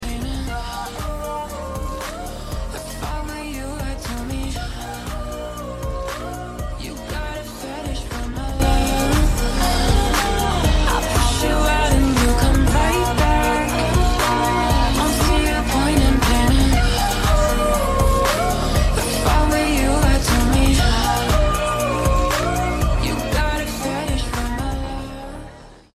mashup edit audio